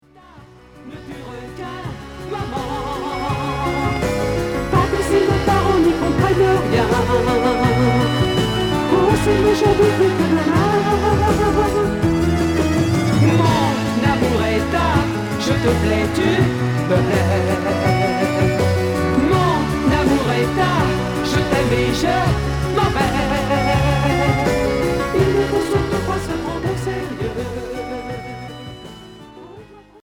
Variété pop Premier 45t retour à l'accueil